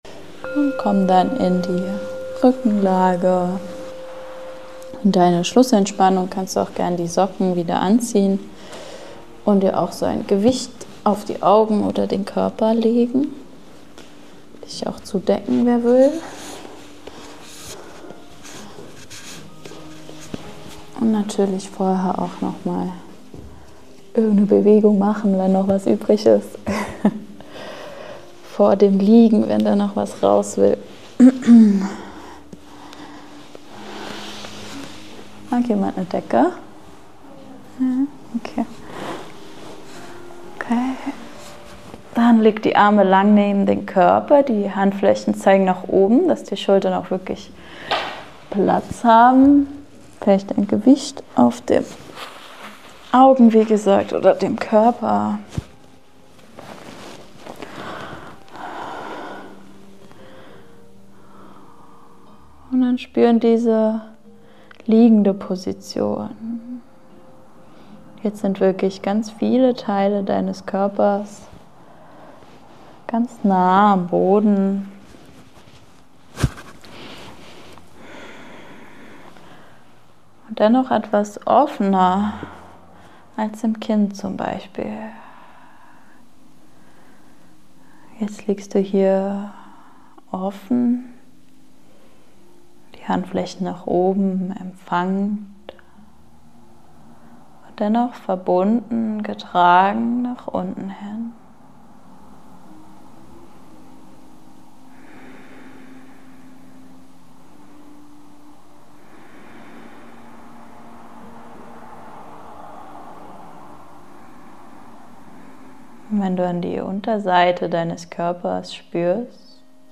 Diese 14-minütige Meditation lädt dich ein, dich im Liegen vollkommen zu entspannen – als würdest du auf einer weichen Wiese in einem geschützten Garten ruhen. Stell dir vor, wie du den Sonnenuntergang beobachtest, während dich die letzten warmen Sonnenstrahlen sanft berühren.
Lass dich von meiner Stimme begleiten und gleite ganz sanft in einen Zustand des Loslassens – vielleicht sogar in den Schlaf.